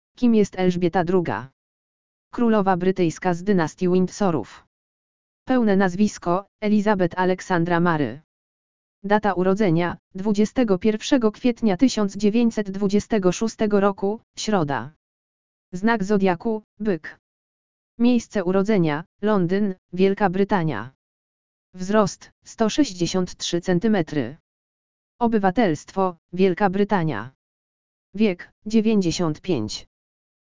LEKTOR AUDIO URODZINY ELŻBIETY II
lektor_audio_urodziny_elzbiety_ii.mp3